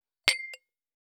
281,ガラスをあてる,皿が当たる音,皿の音,台所音,皿を重ねる,カチャ,ガチャン,カタッ,コトン,ガシャーン,カラン,カタカタ,チーン,カツン,
コップ